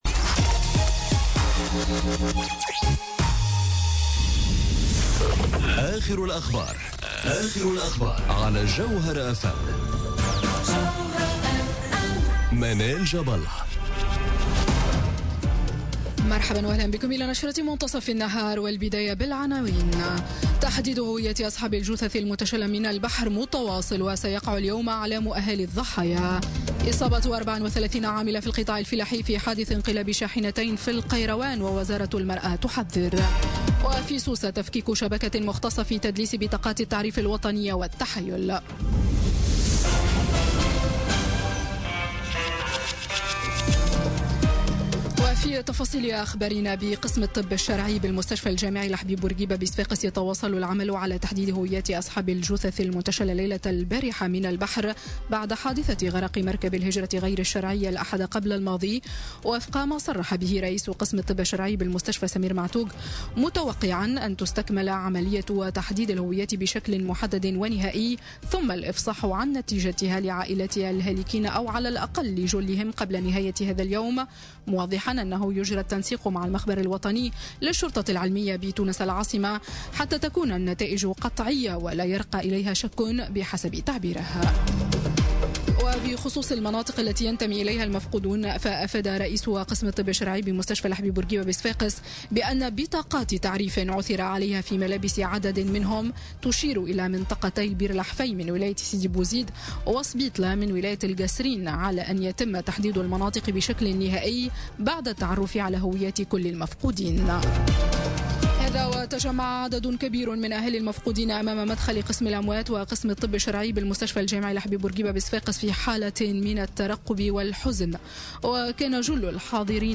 نشرة أخبار منتصف النهار ليوم الإثنين 16 أكتوبر 2017